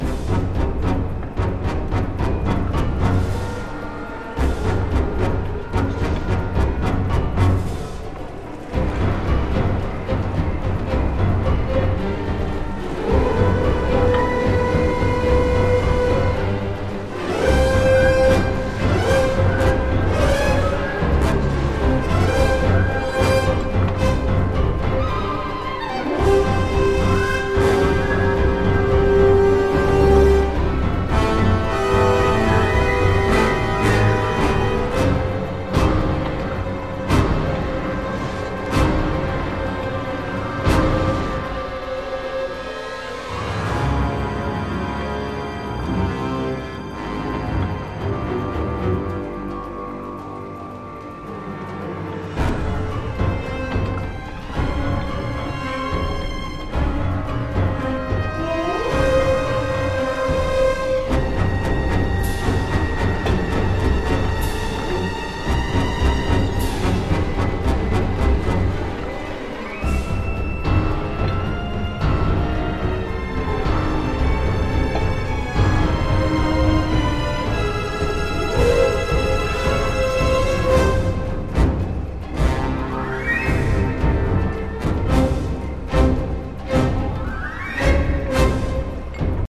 Low quality samples from the game XA music files: